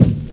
Boom-Bap Kick 66.wav